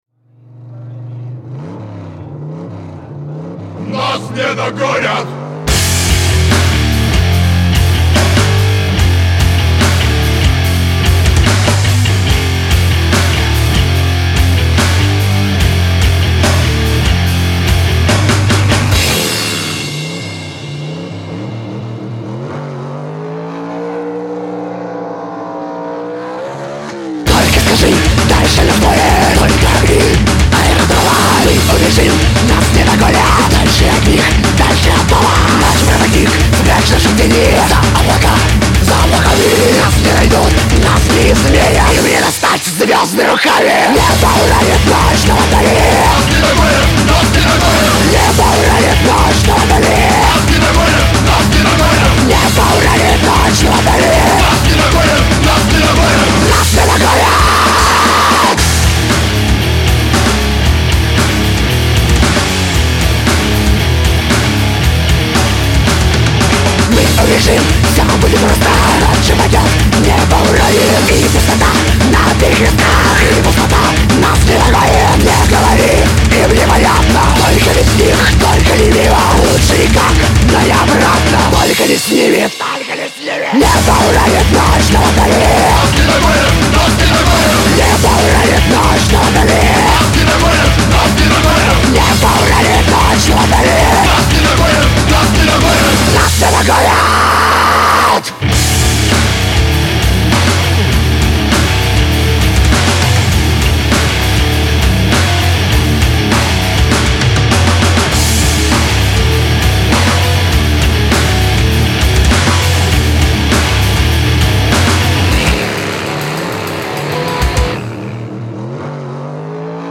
Реально трэшовое исполнение
Смесь Хардрока Техно и треска ваших барабанных перепонок